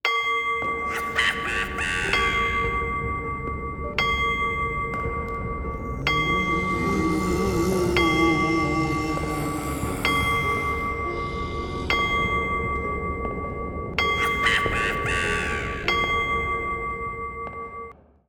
cuckoo-clock-09.wav